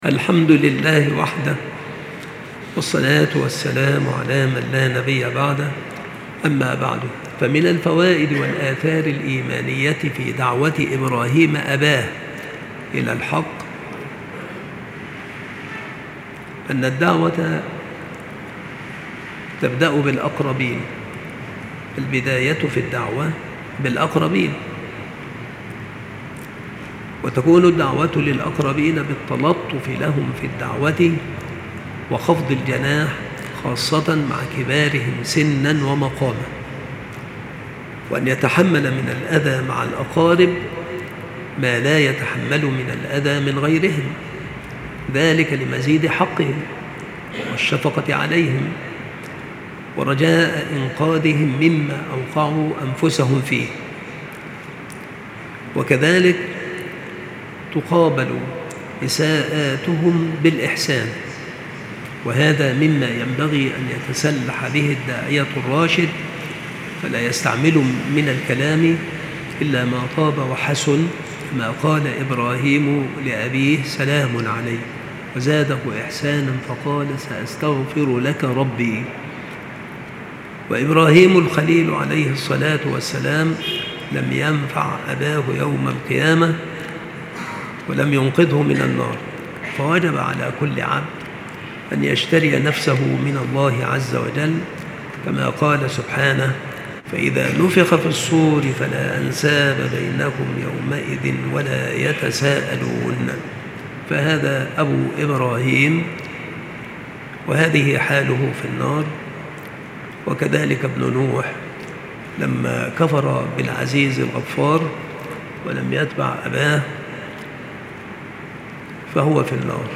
• مكان إلقاء هذه المحاضرة : بالمسجد الشرقي - سبك الأحد - أشمون - محافظة المنوفية - مصر